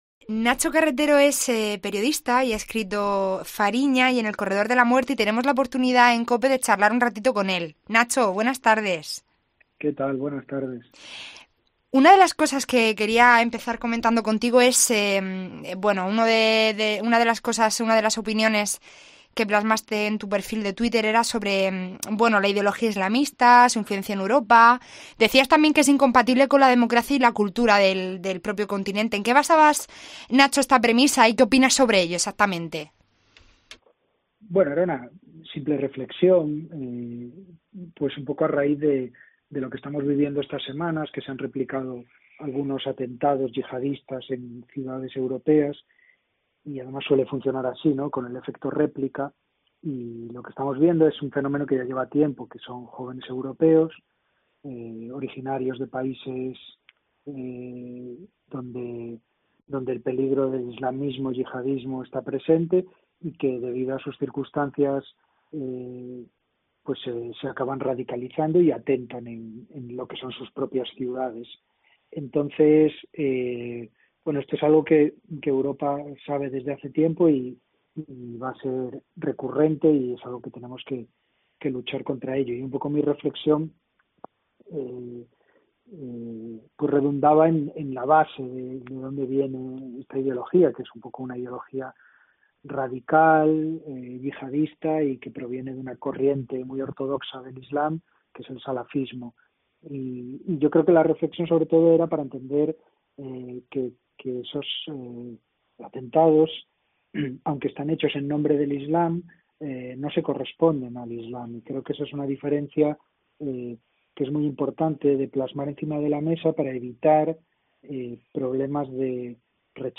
El periodista nos contaba que Europa está en alerta máxima por atentados pese al covid-19 y que este tipo de ataques tienen un carácter cíclico